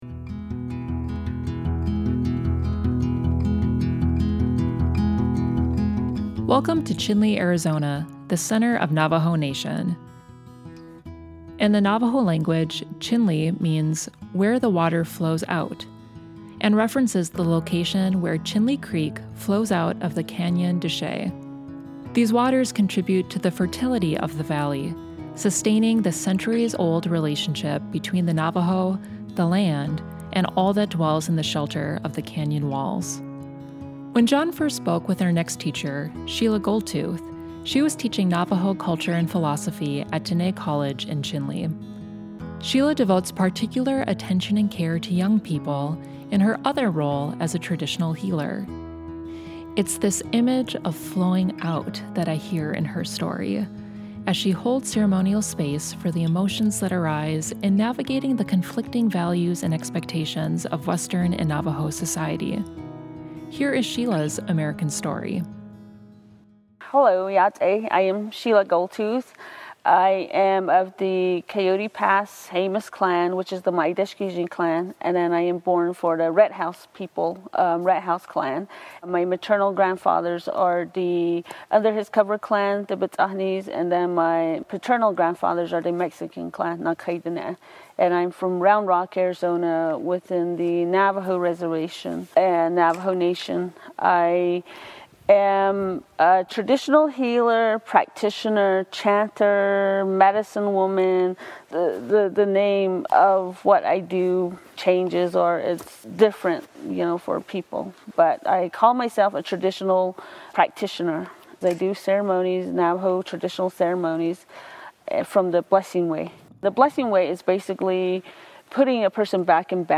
At the time of the interview